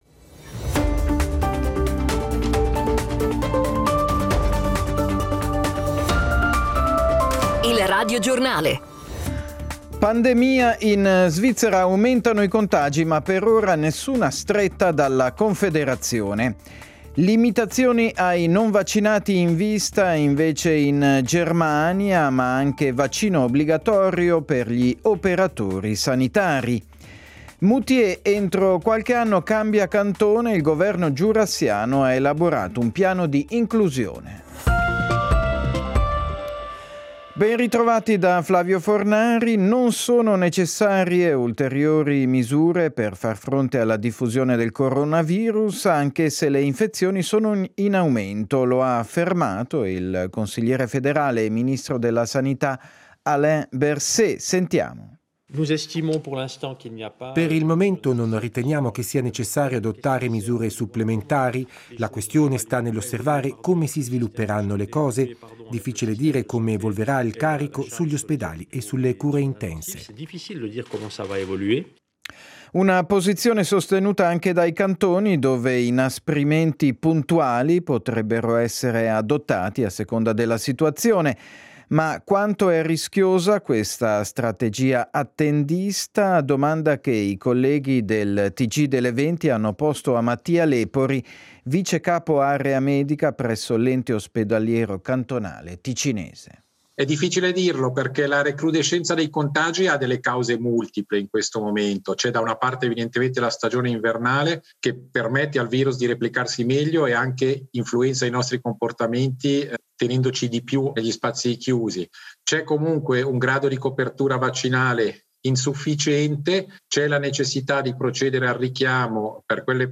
Radiogiornale